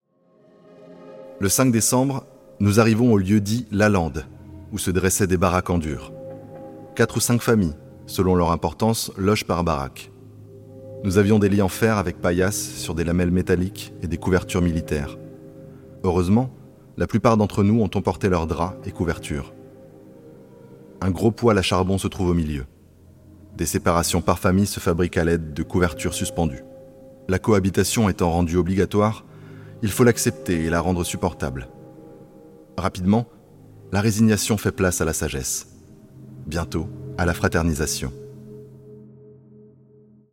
Narration Podcast - Lecture épistolaire 1
- Basse